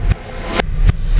Black Hole Beat 15.wav